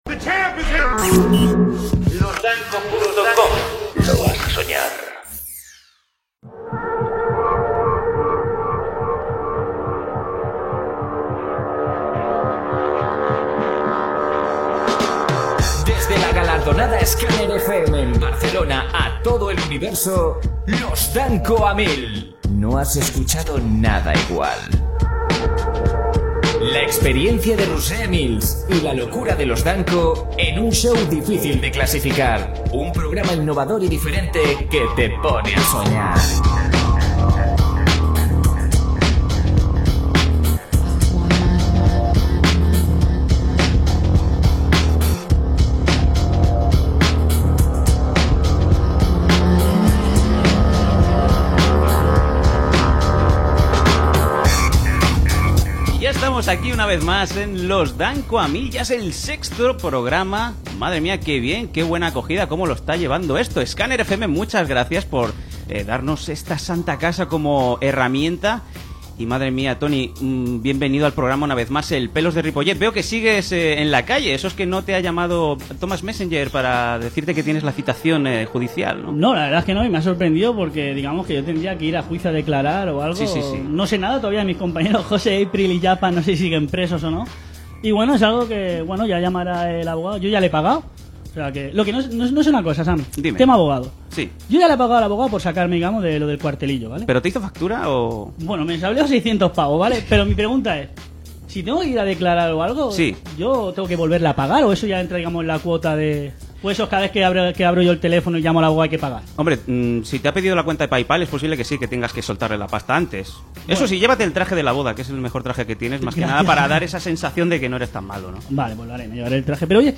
Identificació de l'emissora, careta del programa, presentació, diàleg entre els tres presentadors i entrevista a l'actriu Eva Santolaria.